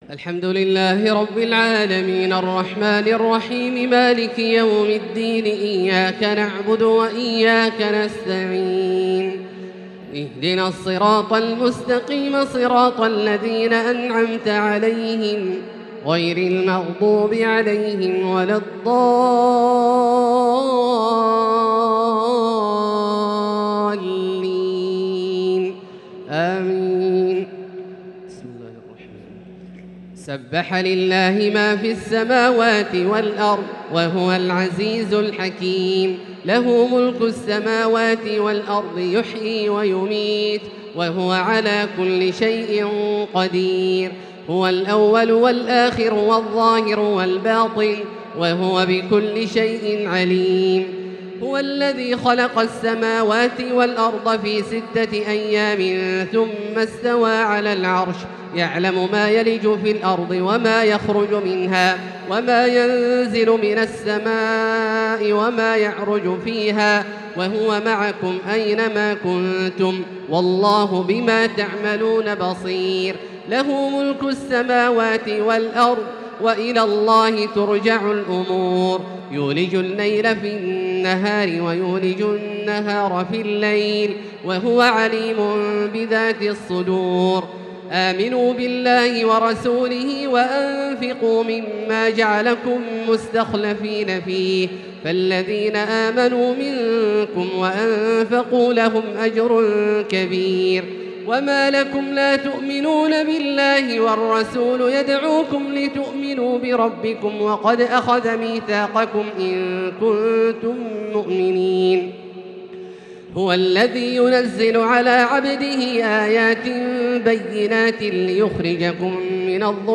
تراويح ليلة 28 رمضان 1443هـ من سورة الحديد إلى سورة الجمعة | taraweeh 28 st niqht Ramadan 1443H from Surah Al-Hadid to Al-Jumu'a > تراويح الحرم المكي عام 1443 🕋 > التراويح - تلاوات الحرمين